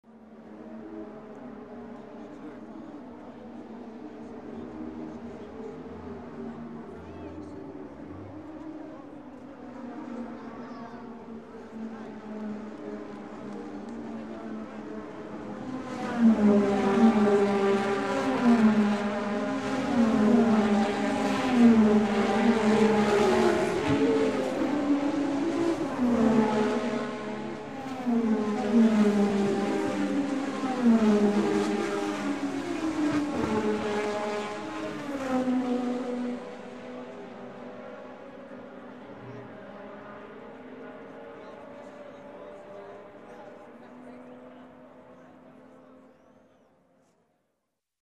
Звуки Формулы-1
Гул болида Формулы-1 в момент пит-стопа